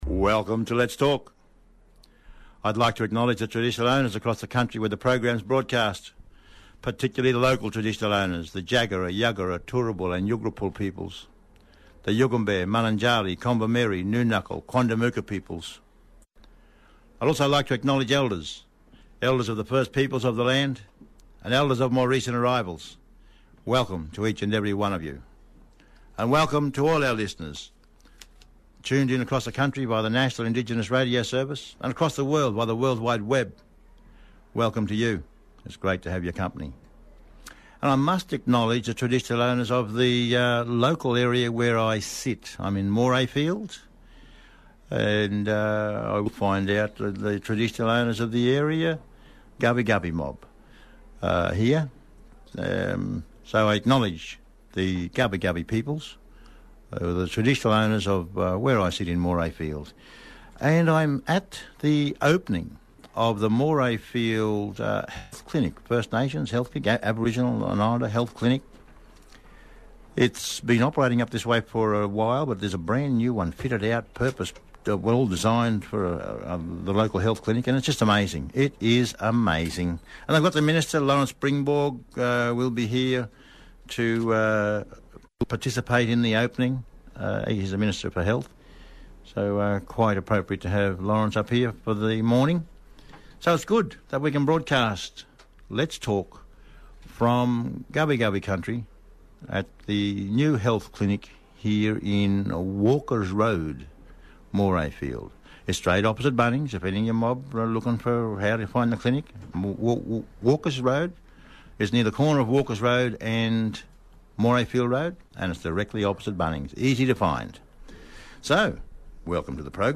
Live broadcast from new community health centre in Morayfield Qld with Institute for Urban Indigenous Health personnel.